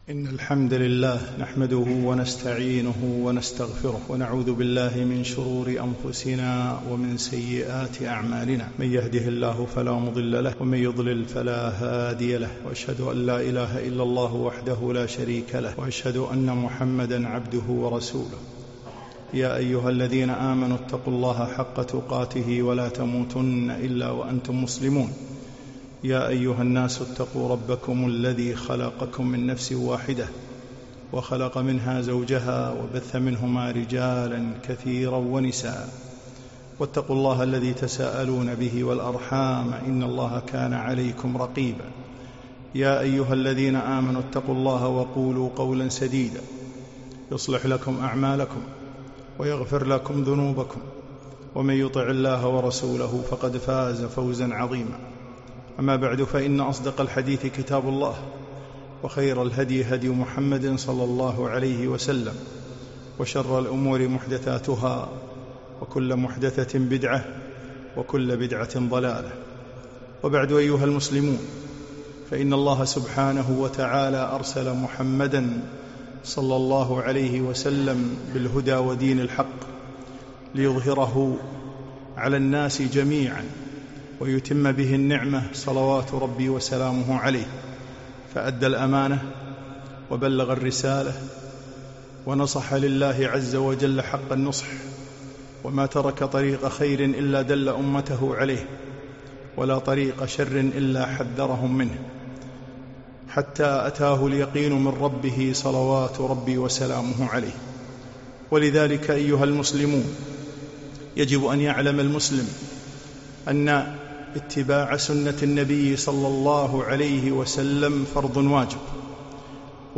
خطبة - البدع في رجب